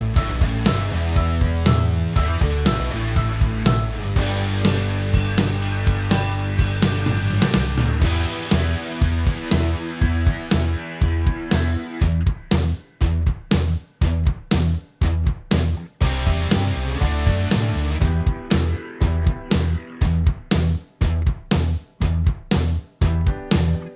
Minus All Guitars Rock 3:49 Buy £1.50